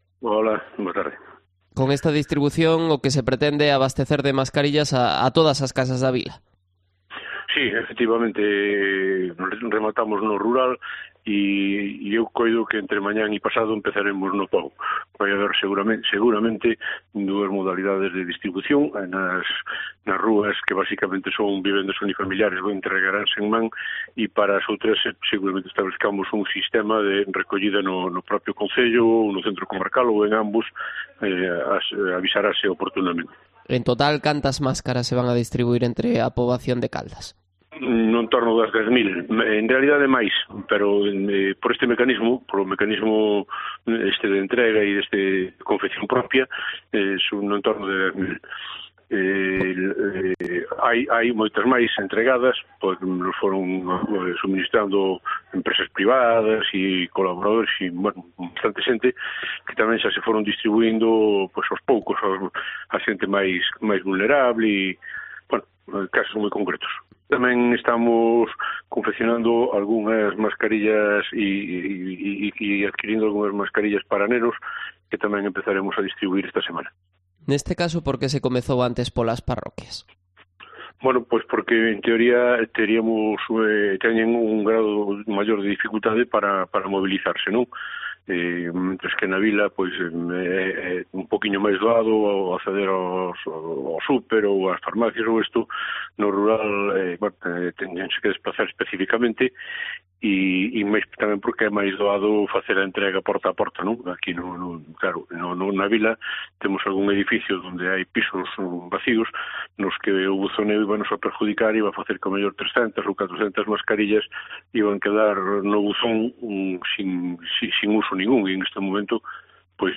Entrevista a Juan Manuel Rey, alcalde de Caldas de Reis